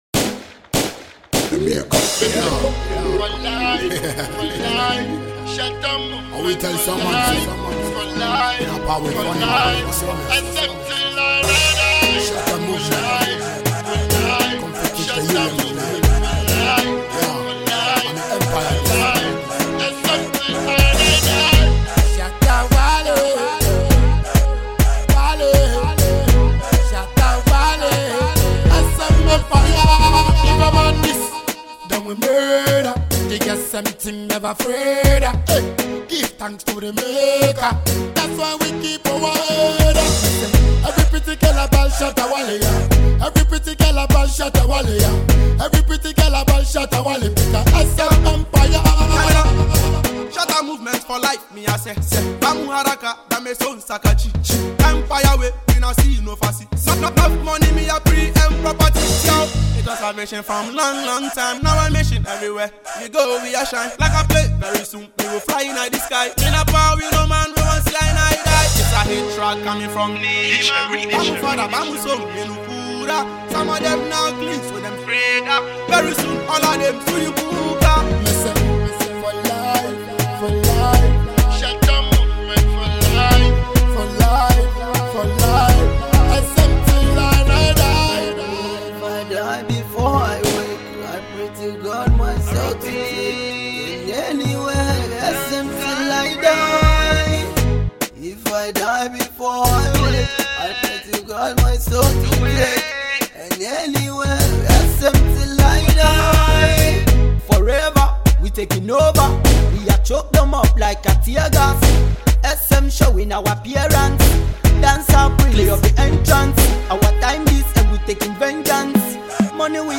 Dancehall/HiphopMusic
groovy and fast tempo song